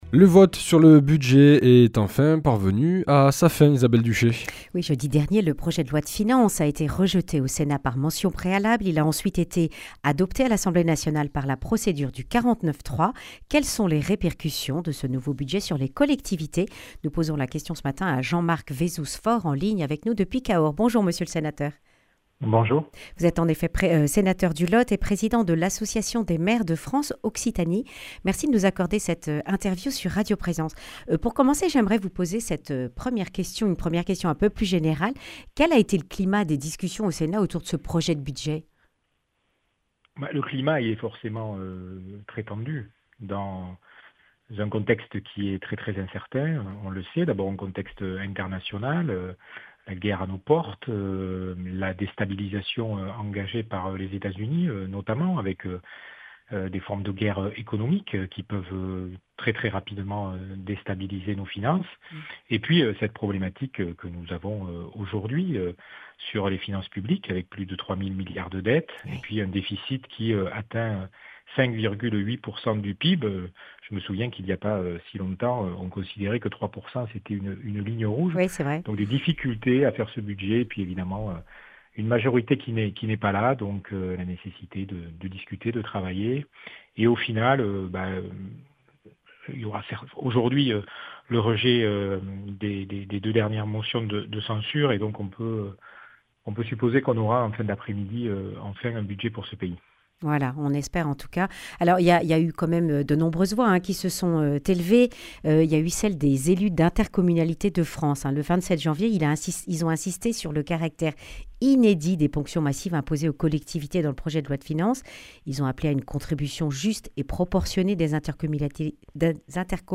Réaction de Jean-Marc Vassouze-Faure, sénateur du Lot et président de l’association des maires de France Occitanie. Il évoque aussi les prochaines élections municipales.
Accueil \ Emissions \ Information \ Régionale \ Le grand entretien \ Vote du budget, quelles répercussions pour les collectivités d’Occitanie ?